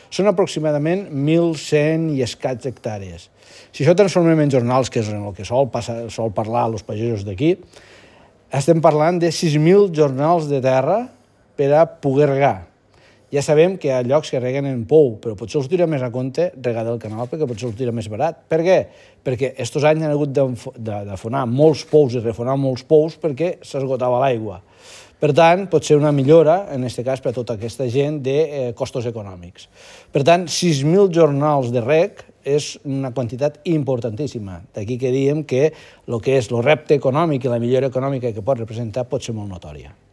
Sisco Ollé, regidor d’Agricultura de l’ajuntament de Roquetes
Sisco-Olle-regidor-dAgricultura-de-lAjuntament-de-Roquetes.mp3